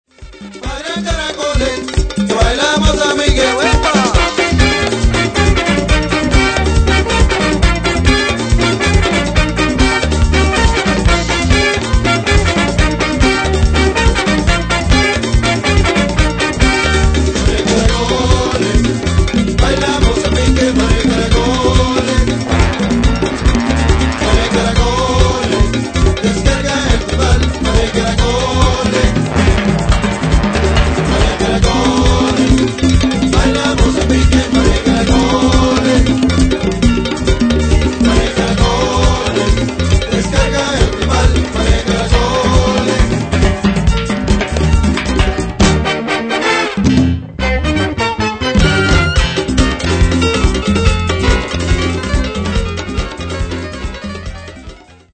African rhythms are fused with modern elements
Guira